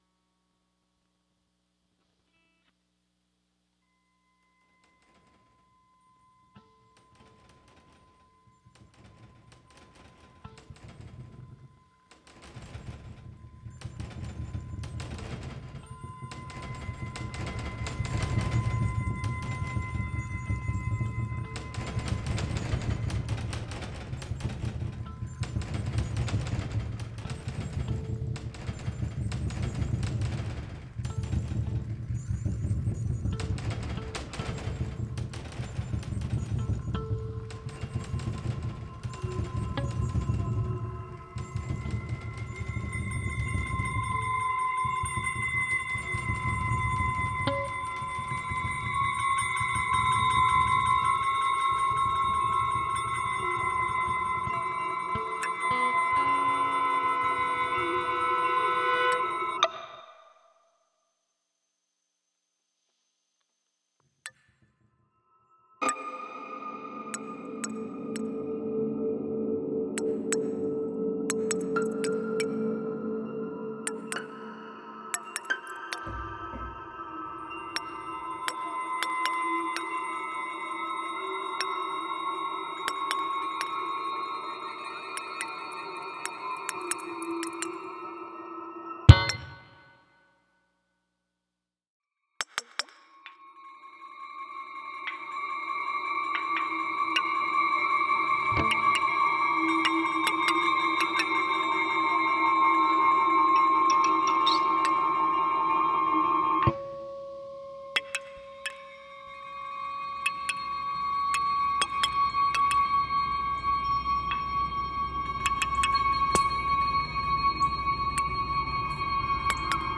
acousmatic, fixed-media works
fixed media sound
Initially, I developed a software instrument and recorded ten iteratively-layered improvisations, which then served as a backdrop or canvas for the piece. Shorter passages were then added to the mix, generated using a wide variety of techniques ranging from musical feature analysis to improvised electronic guitar. The process of working on the piece became a bit less haphazard in the striping away of material; by carving out silence and space, distinctions between the materials became possible, and ultimately, meaningful.